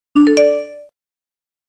notice.mp3